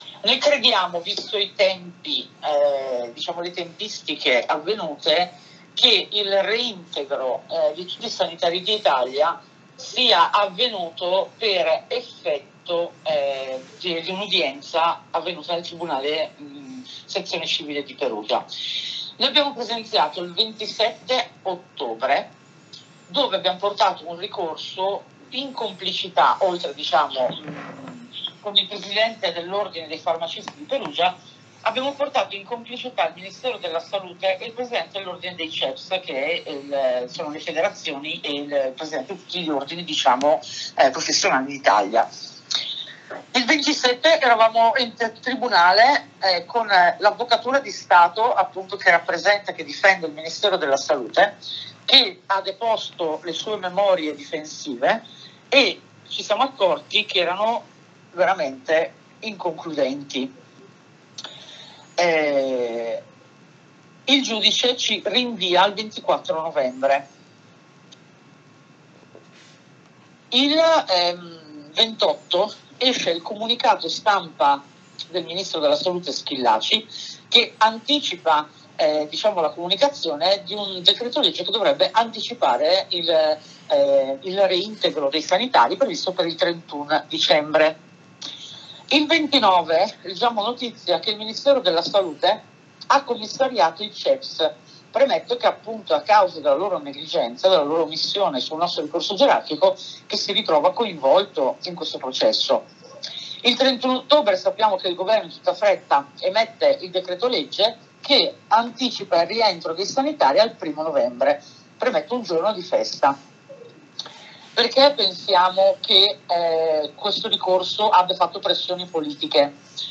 QUI SOTTO IL BRANO DELL'AUDIO-INTERVISTA